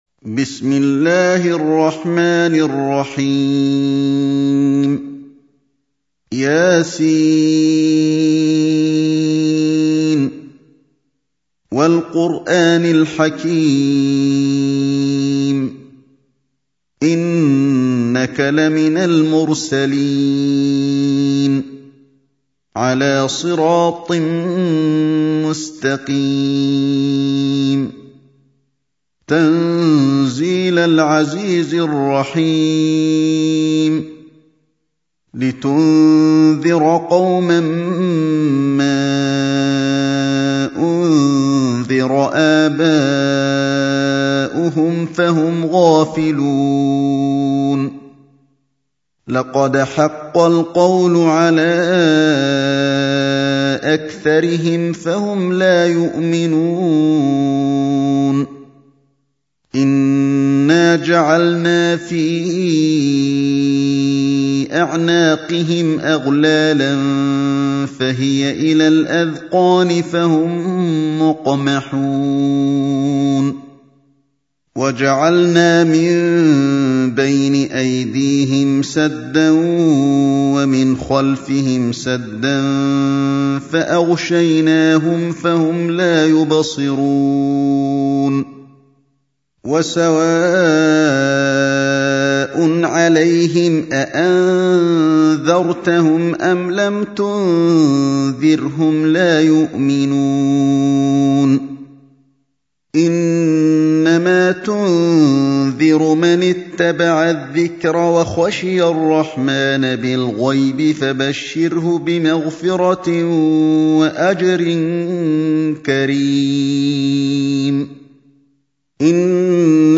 سورة يس | القارئ علي الحذيفي